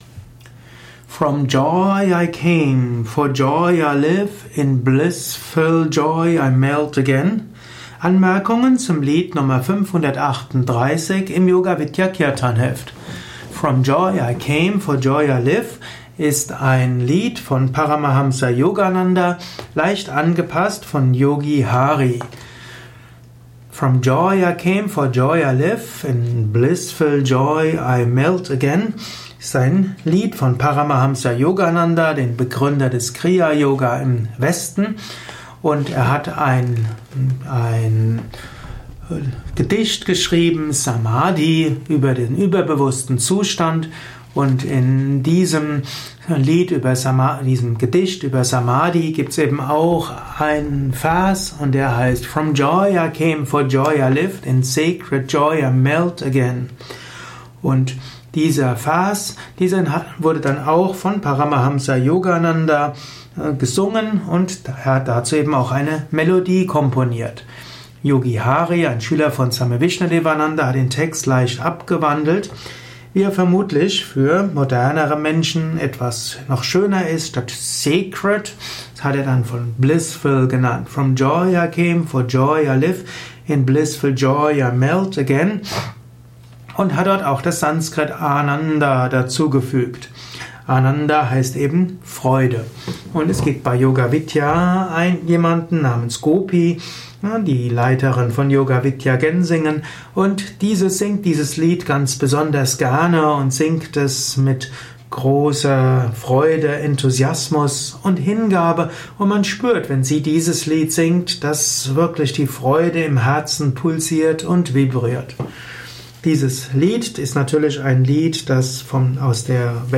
624 im Yoga Vidya Kirtanheft , Tonspur eines Kirtan